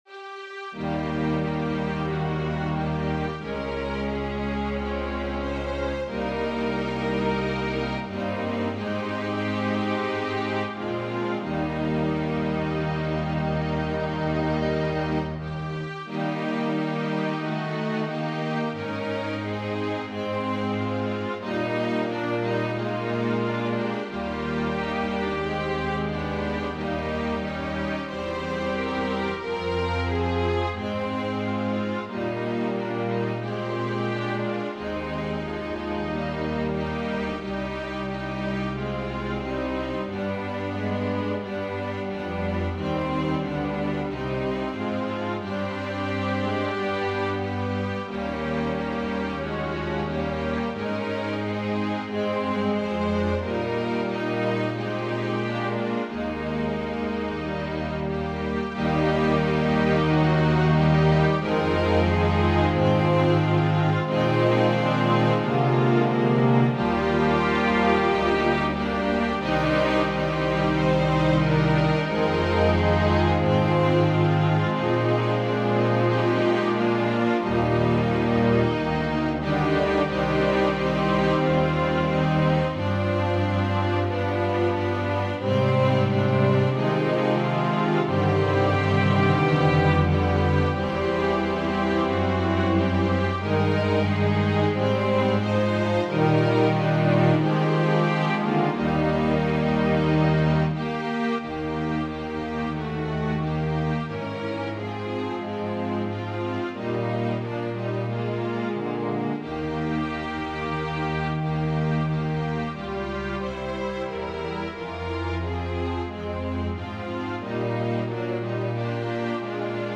Organ/Organ Accompaniment
Voicing/Instrumentation: Organ/Organ Accompaniment We also have other 51 arrangements of " O Little Town Of Bethlehem ".